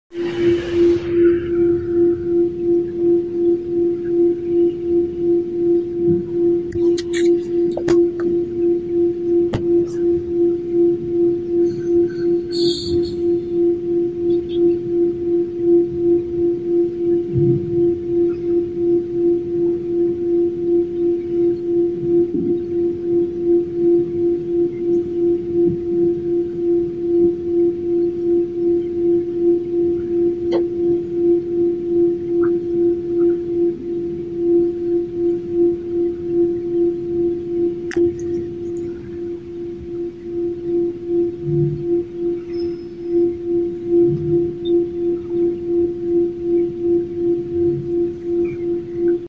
interesante sonido 🛸 que provenía del cielo